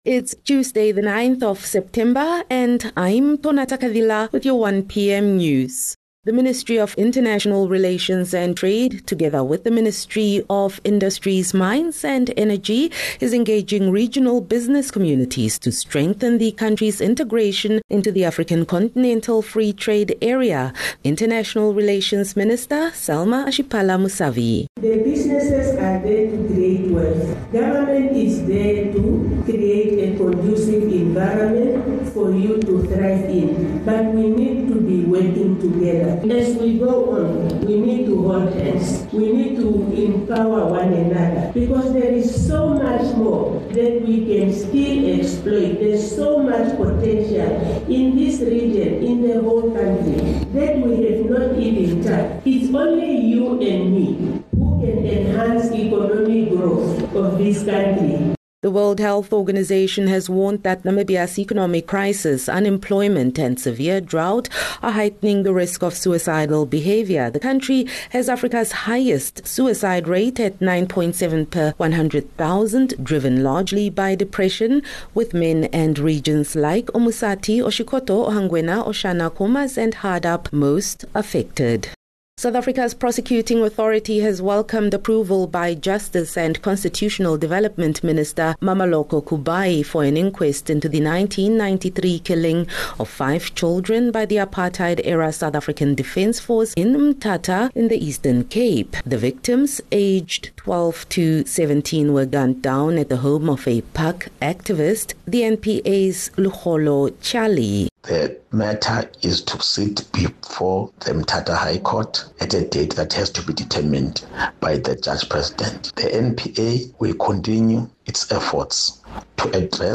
9 Sep 9 September - 1 pm news